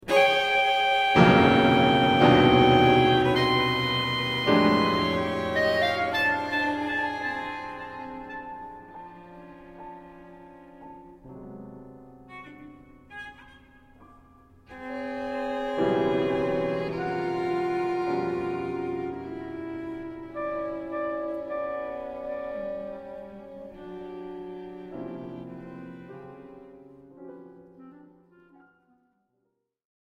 off air recording